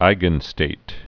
(īgən-stāt)